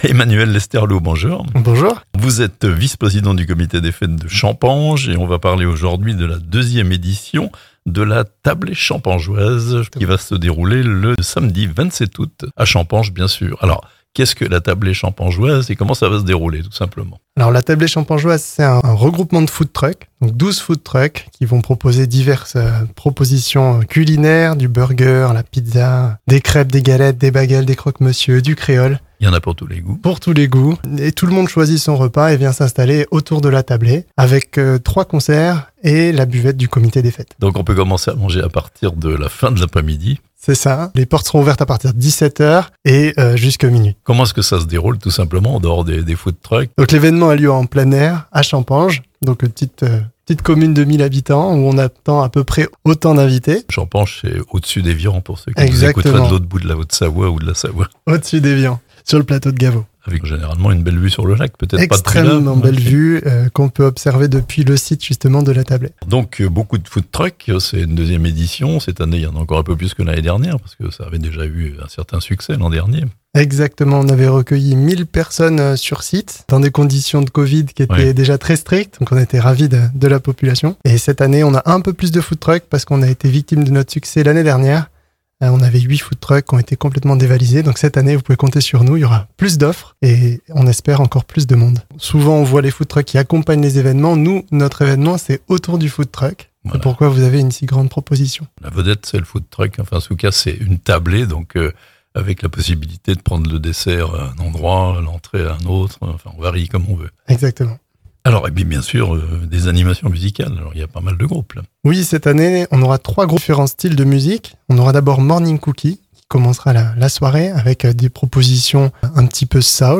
Présentation de cette manifestation